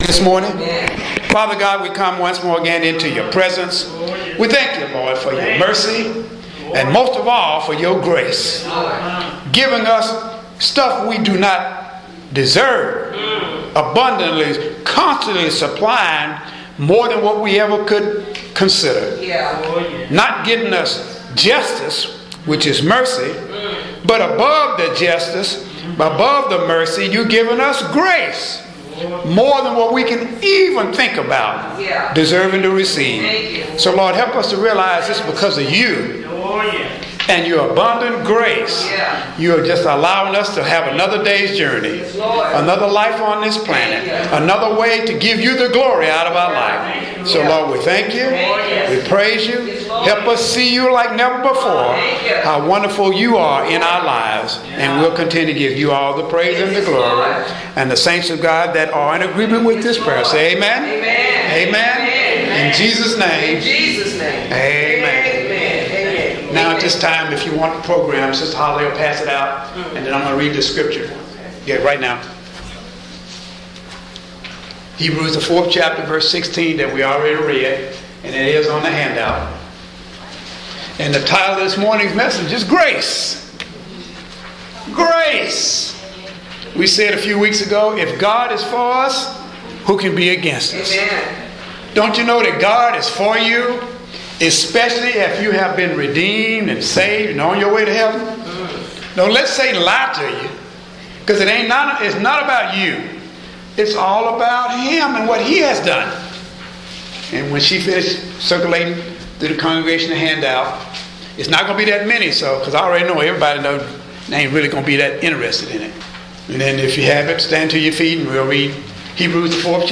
Posted in Audio Sermons , Audio Sermons Tagged with grace